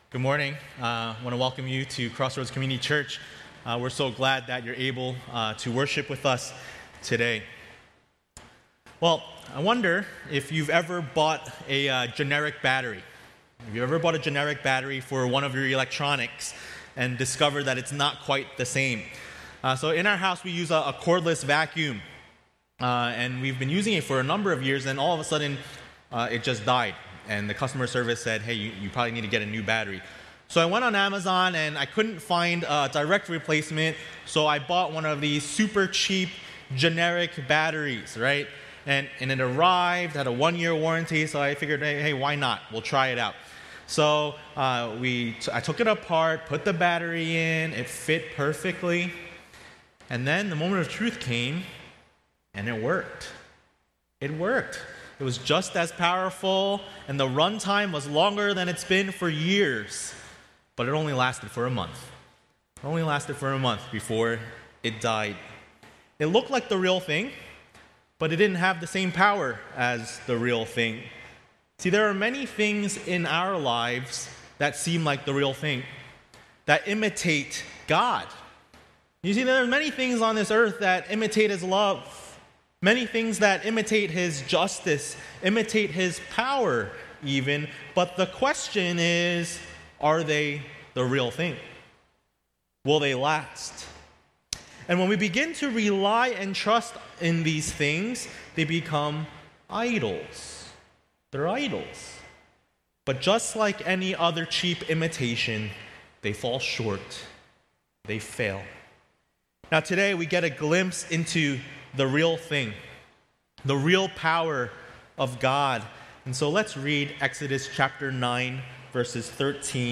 A message from the series "Sojourners: Exodus ."